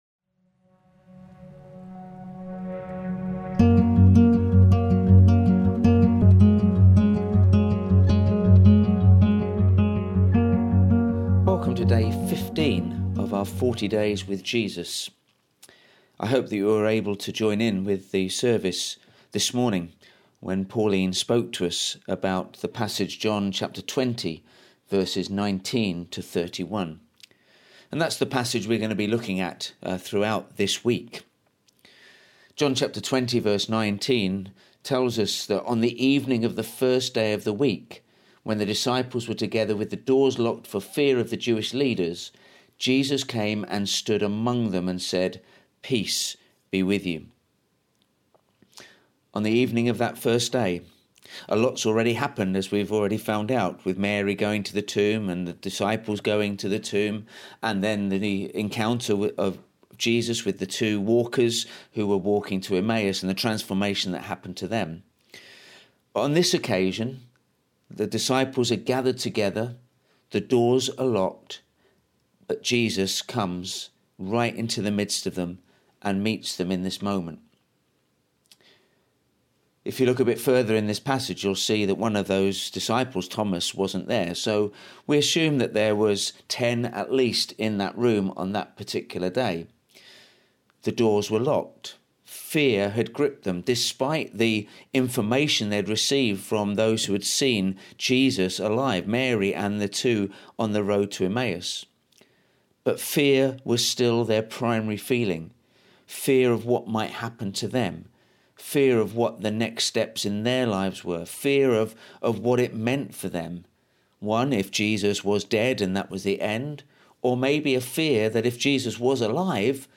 We will be posting short, daily reflections as we journey through the encounters people had with the risen Jesus.